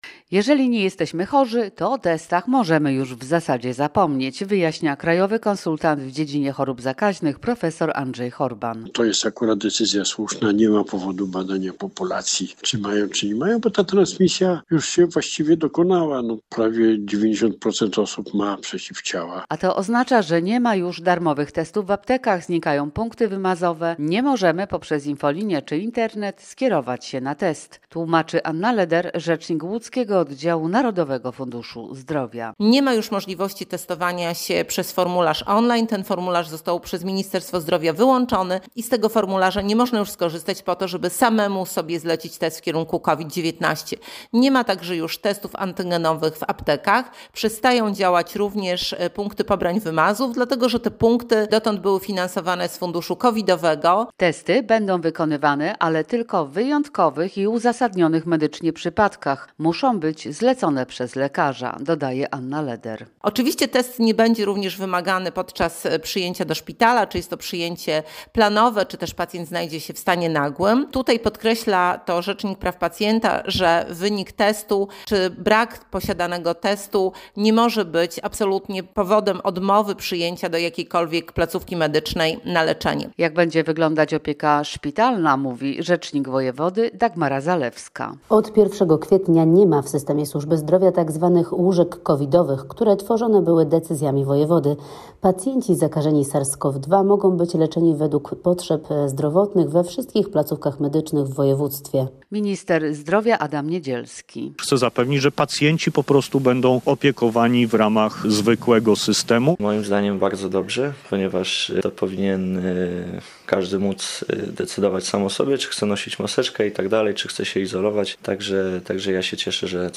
Posłuchaj relacji i dowiedz się więcej: Nazwa Plik Autor Koniec pandemii coraz bliżej?